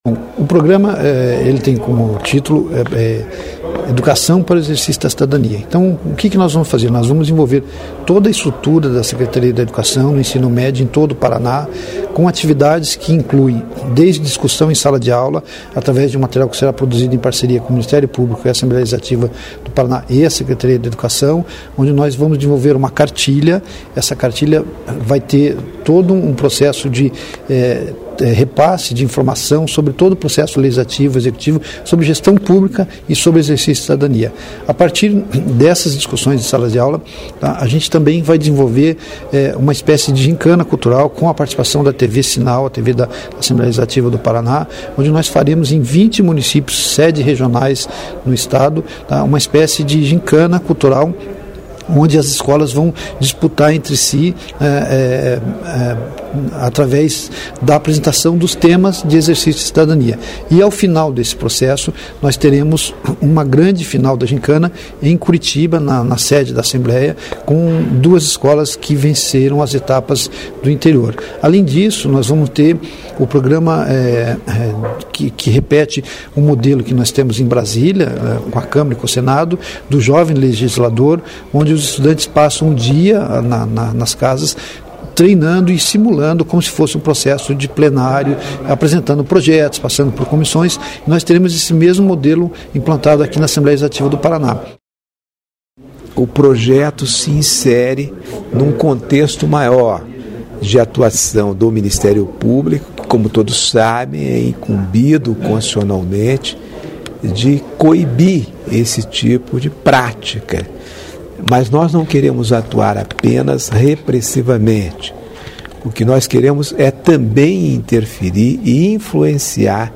Confira (em sequencia, no mesmo arquivo) as entrevista dele, do procurador-geral de Justiça, Gilberto Giacoia, e do secretário de Estado da Educação, Paulo Schimidt: